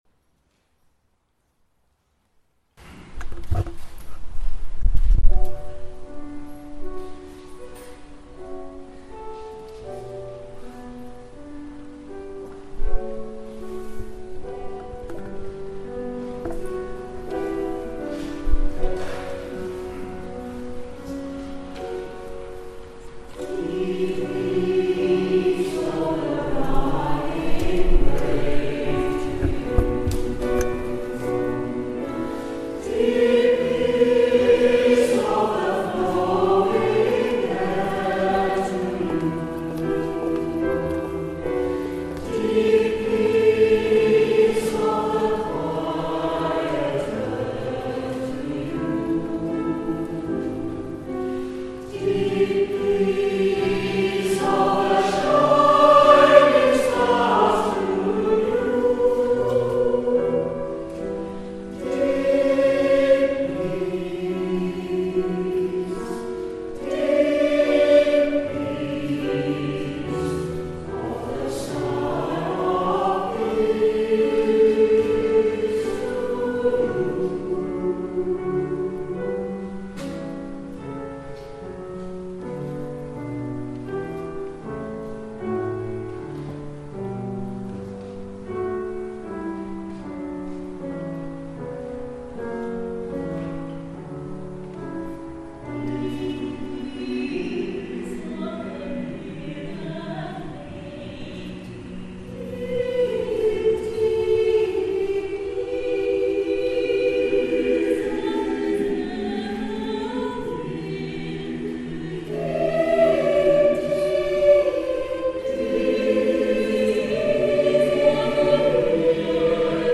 At the Simon Balle Choral Concert 2017